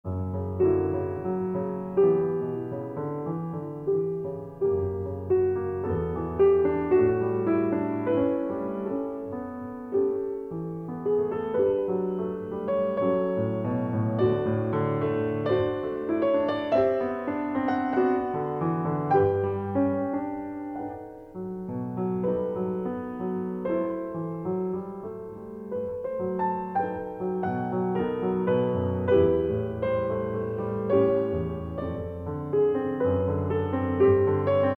鋼琴